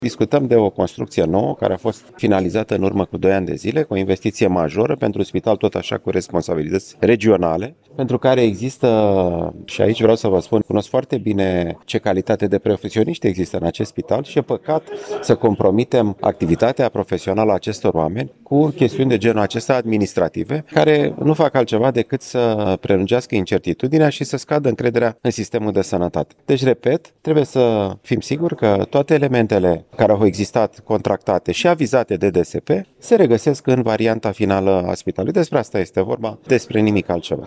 La inaugurarea laboratorului de neuroradiologie de la Spitalul de Neurochirurgie din Iași a fost prezent și fostul ministru al Sănătății, deputatul PSD Alexandru Rafila.